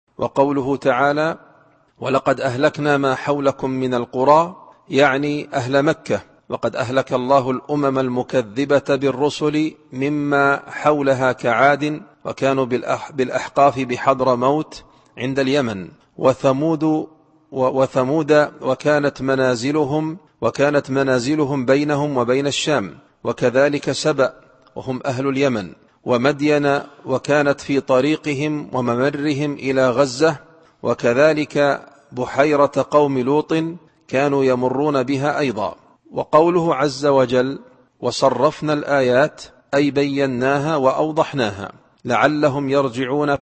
التفسير الصوتي [الأحقاف / 27]